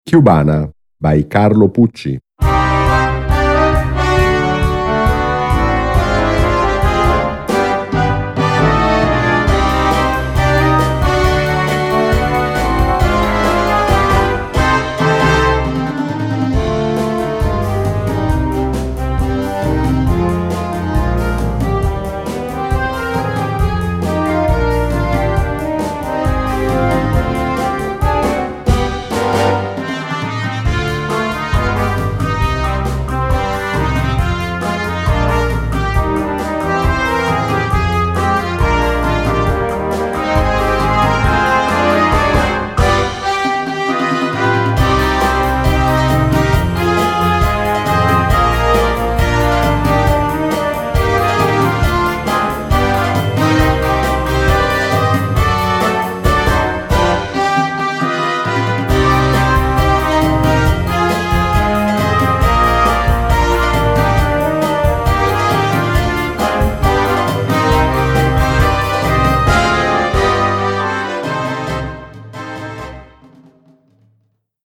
Gattung: Konzertante Unterhaltungsmusik
Besetzung: Blasorchester